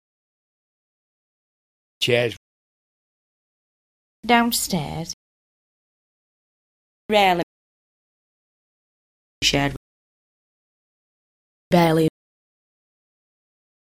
Listen to nonrhotic examples from the Burnley-Colne area:
Example 4: nonrhotic SQUARE vowels
burnleysquare.mp3